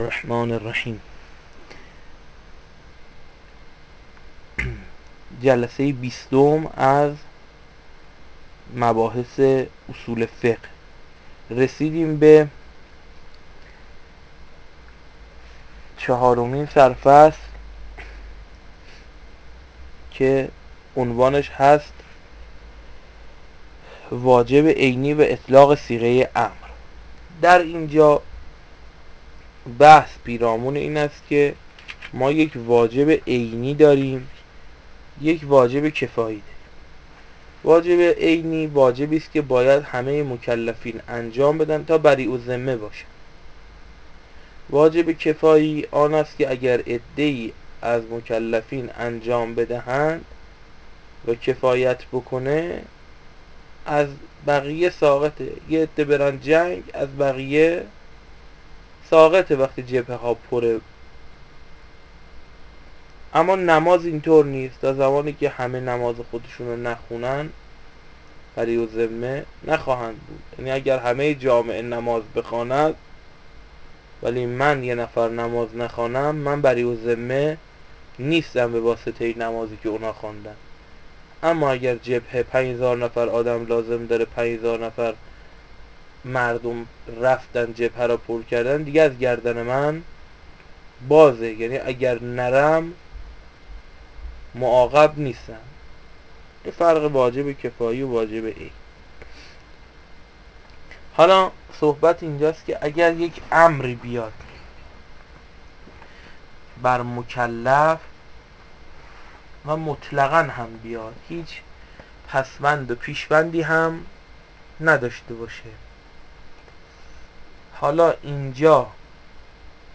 دروس اصول فقه